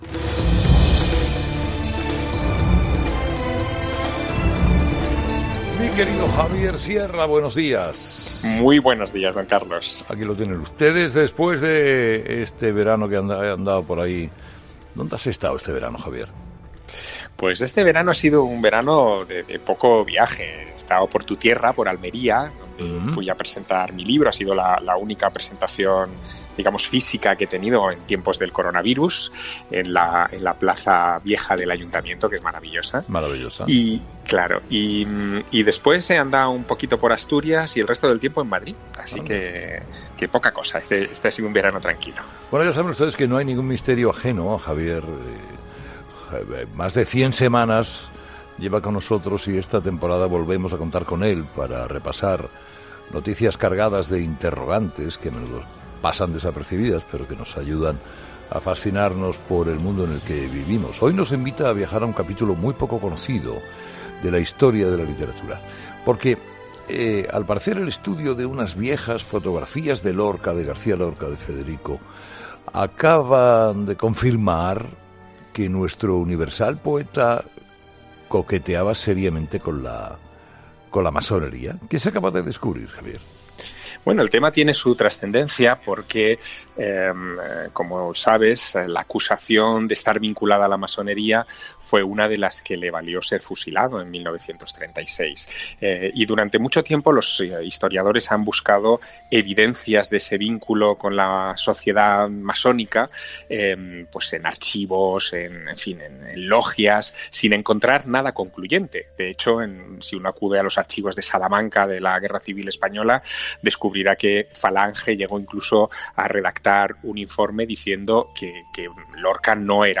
Nuestro colaborador, Javier Sierra, nos cuenta cuáles podrían ser los nuevos descubrimientos en torno a la figura del poeta a raíz de un nuevo estudio